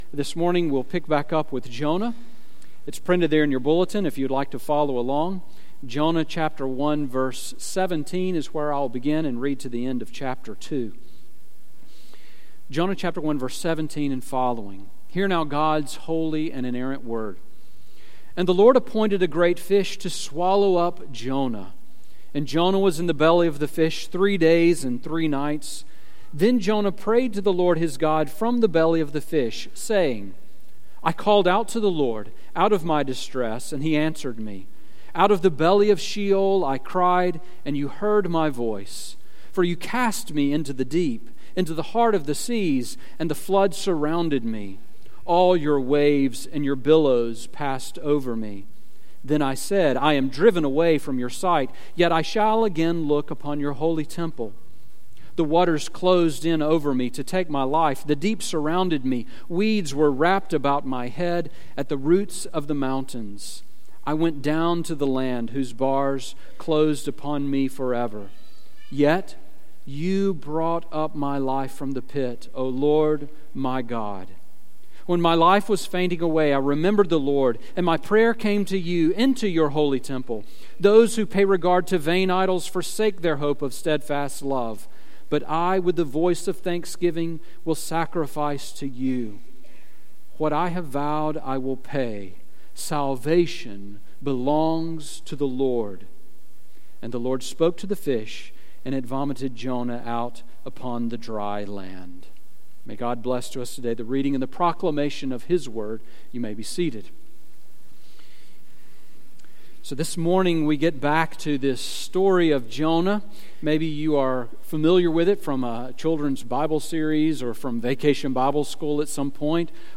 Sermon on Jonah 1:17-2:10 from July 17